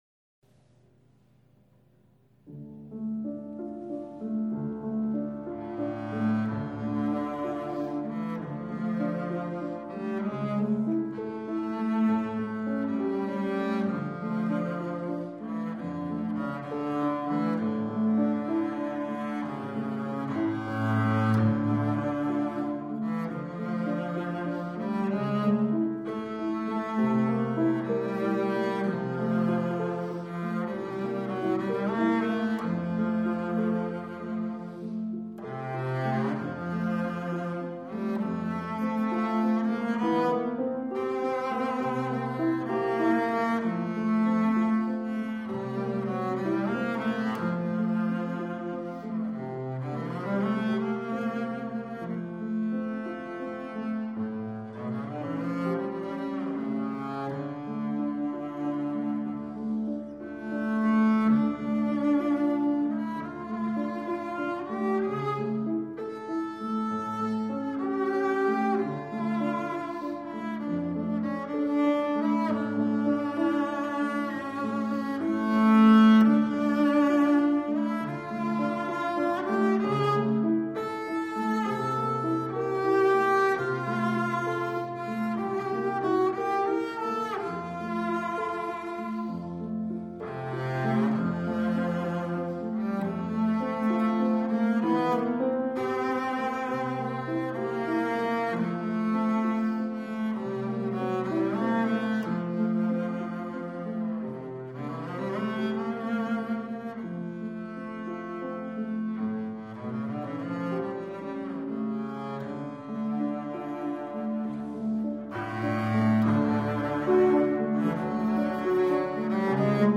Variaciones para una cuerda. Bajo y piano.